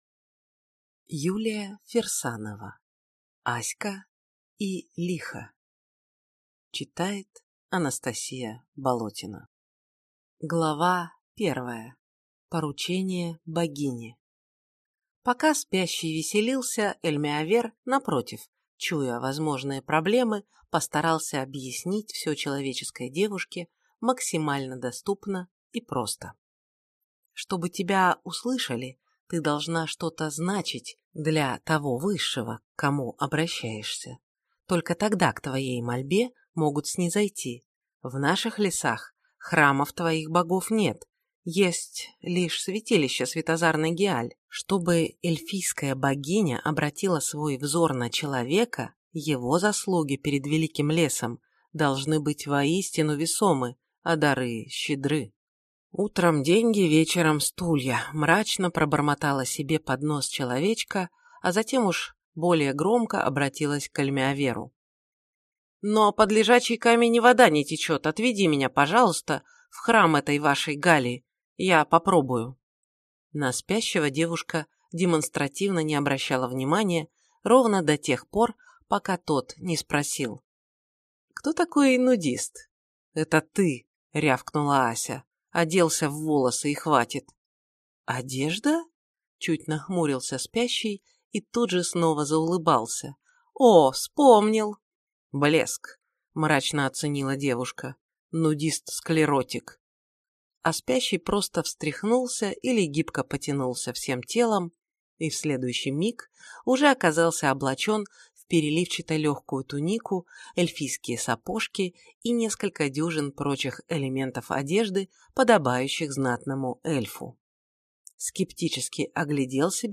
Аудиокнига Аська и Лихо | Библиотека аудиокниг